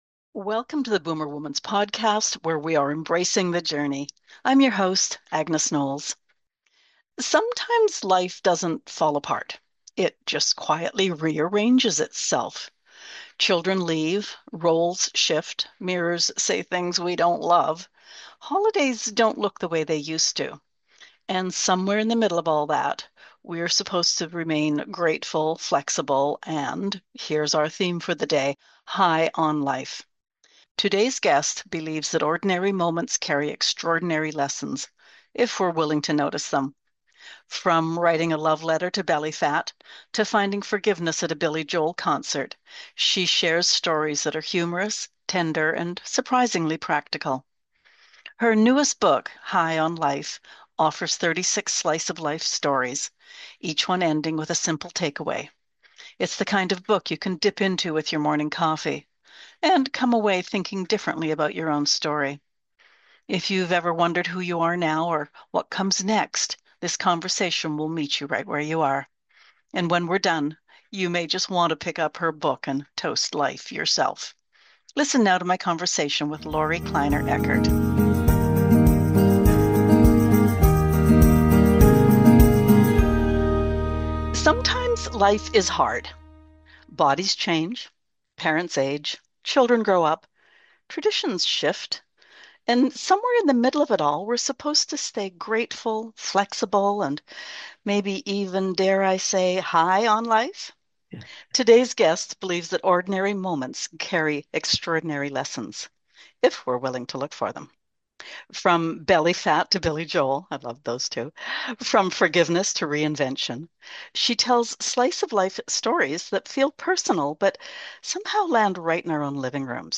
What if midlife isn’t about dramatic reinvention — but about noticing the wisdom tucked inside ordinary moments? In this conversation